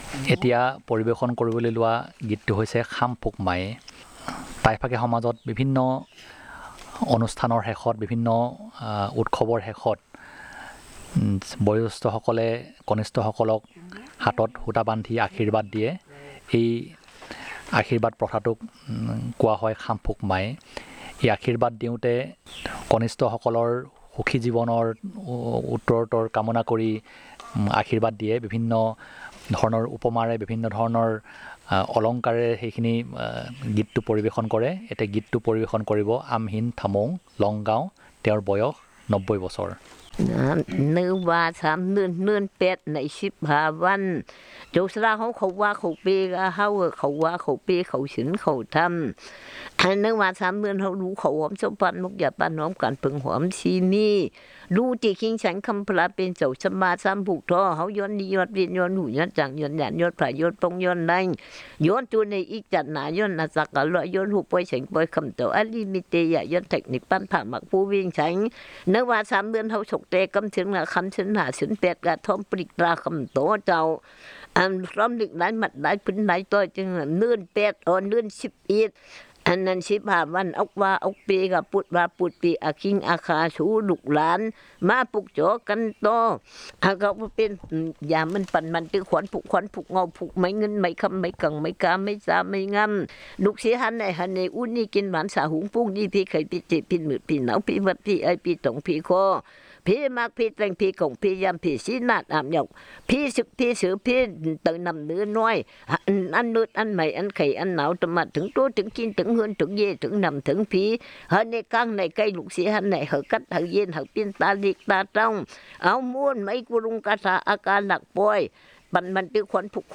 Performance of a song about religious and cultural activity